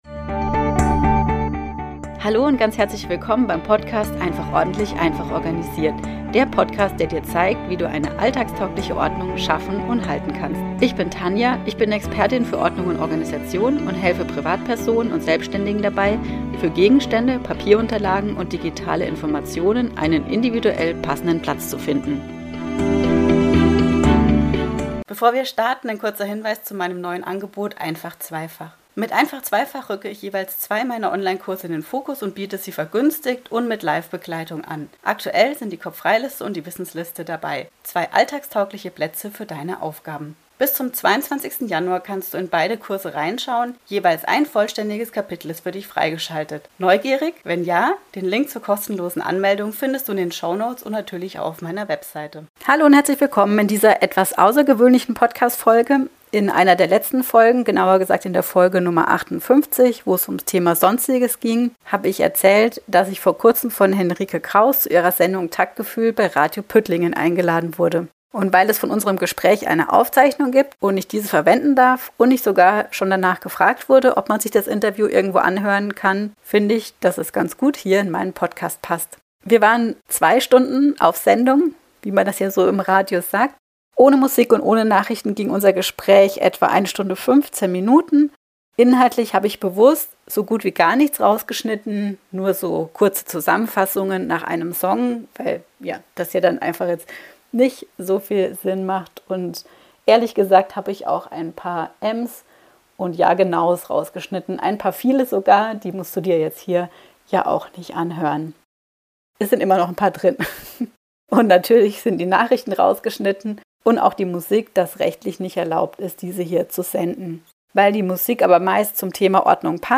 Radiointerview Teil 1 (Folge 61) ~ einfach ordentlich - einfach organisiert Podcast
Als Gast in der Sendung Taktgefühlt bei Radio Püttlingen